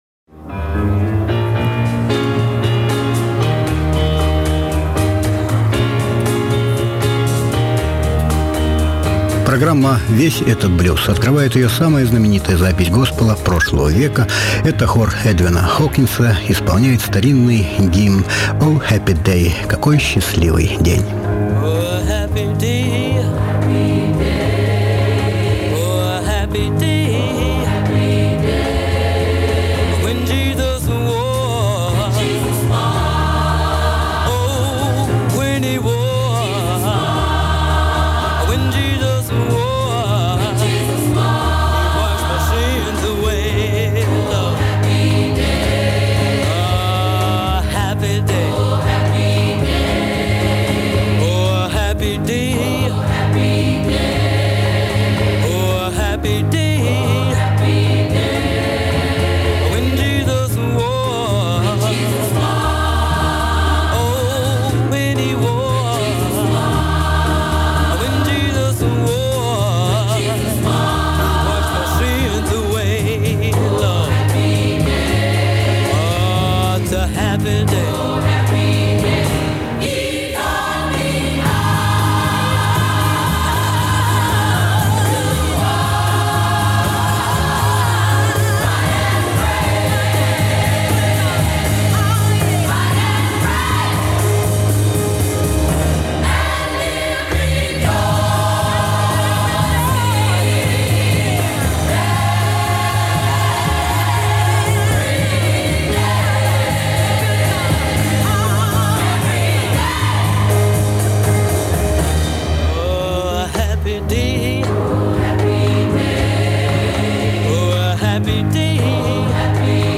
Сплошные блюзовые юбилеи!